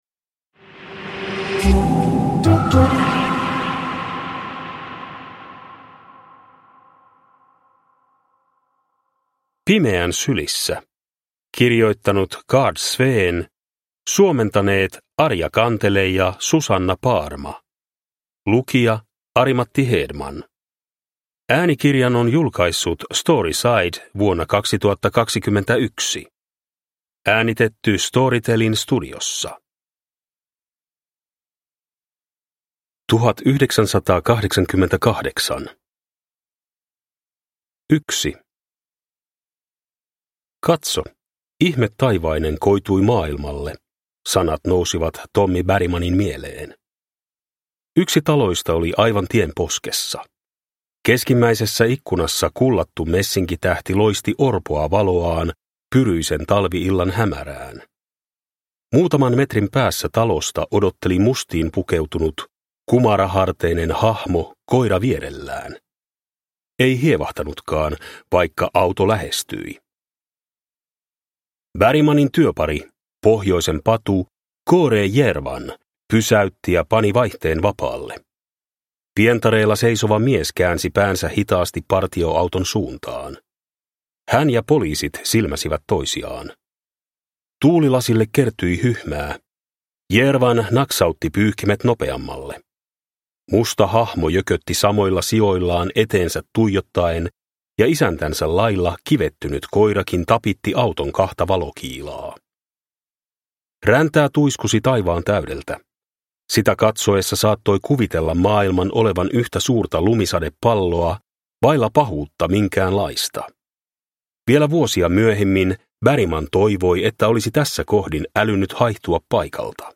Pimeän sylissä – Ljudbok – Laddas ner